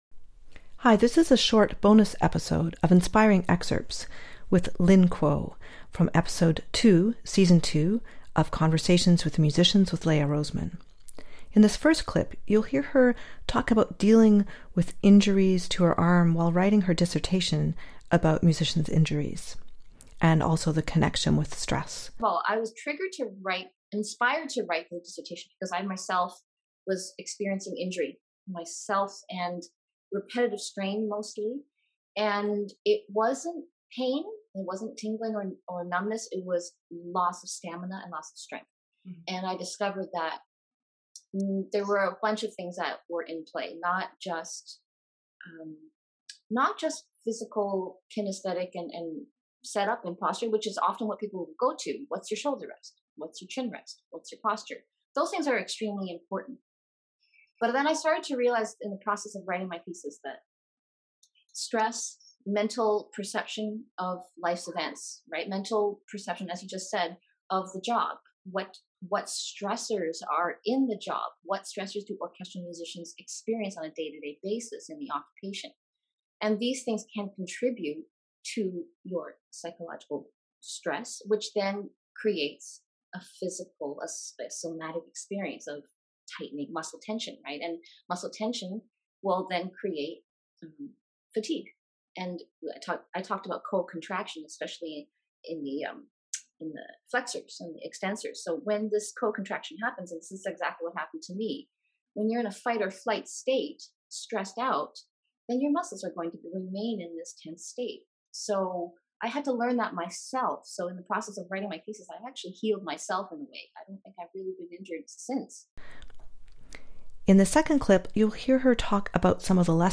Conversations with Musicians